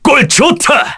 Baudouin-Vox_Skill4-2_kr.wav